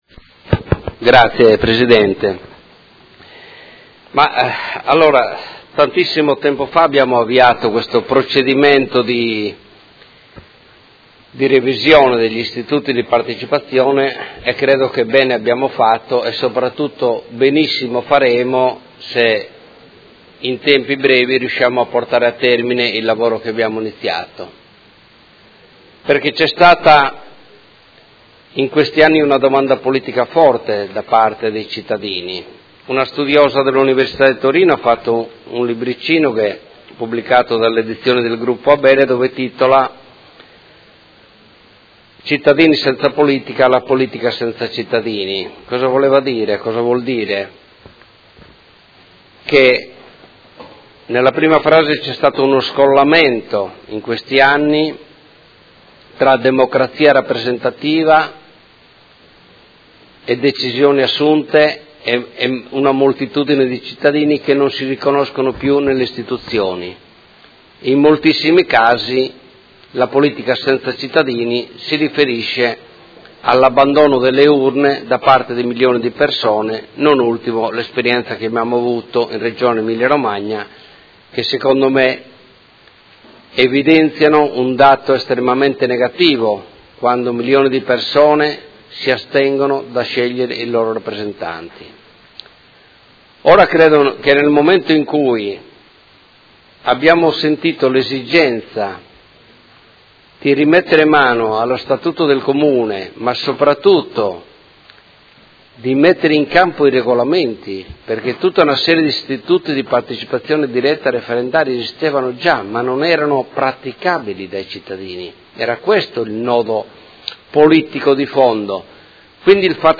Seduta del 15/03/2018. Dibattito su proposta di deliberazione: Revisione del Regolamento sugli istituti di partecipazione dei cittadini del Comune di Modena